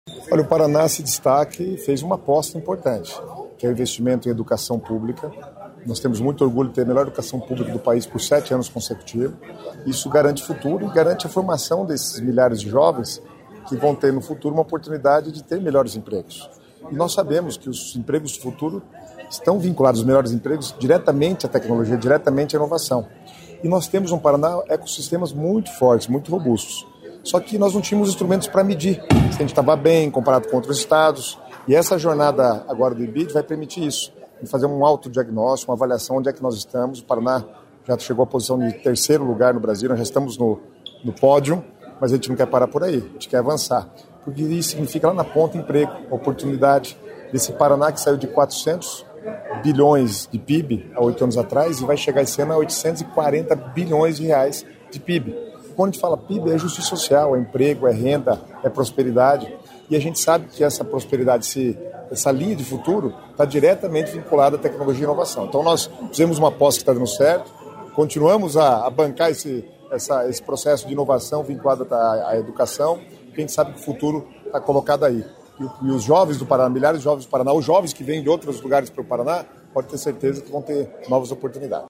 Sonora do secretário Estadual das Cidades, Guto Silva, sobre o lançamento da Jornada IBID 2030